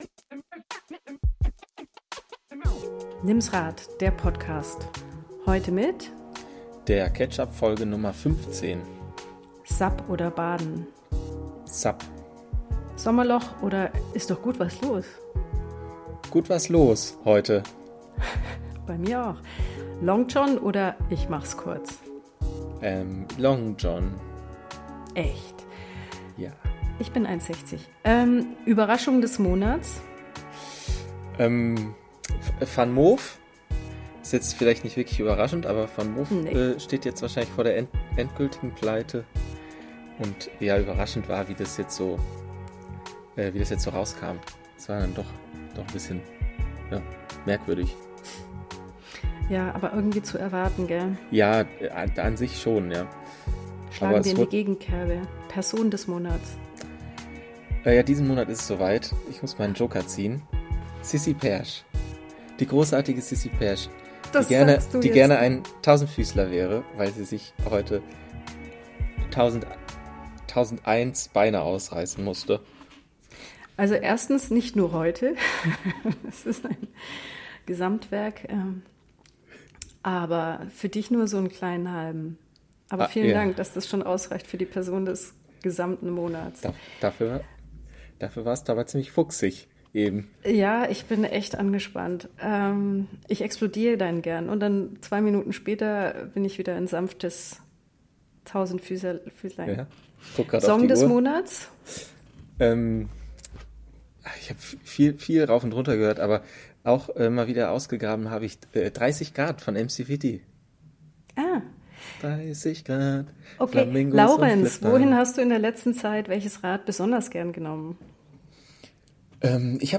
versuchen gegen die Presslufthämmer anzureden